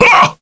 Gau-Vox_Damage_kr_02.wav